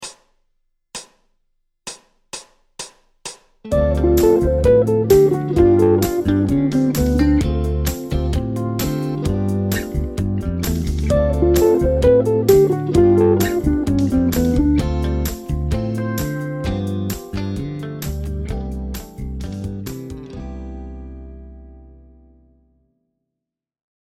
Arpège pivot préparatoire à une descente de la gamme